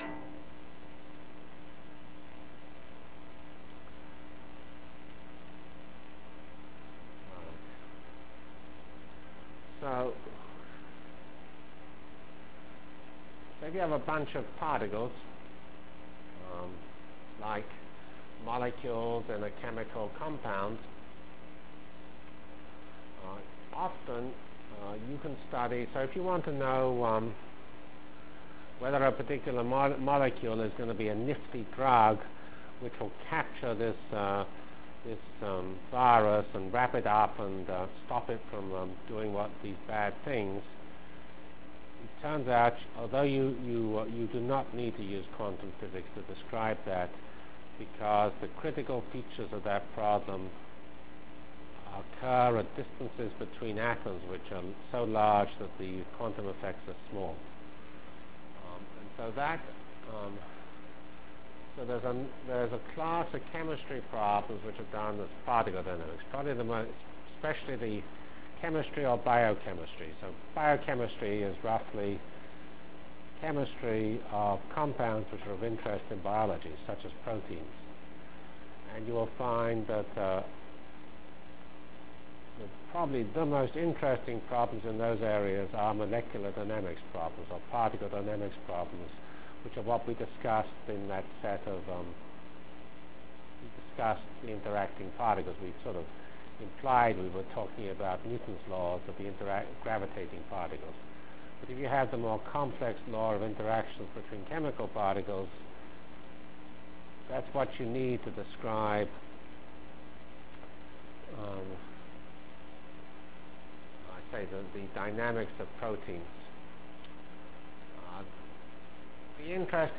From CPS615-Physical Simulation Techniques and Structure of CFD Equations Delivered Lectures of CPS615 Basic Simulation Track for Computational Science -- 14 November 96.